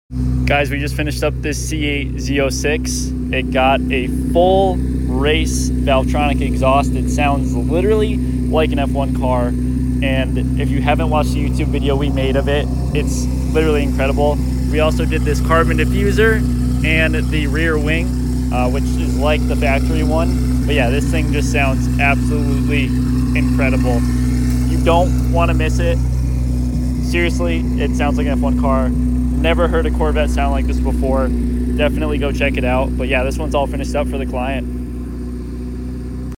Sounds like an F1 car sound effects free download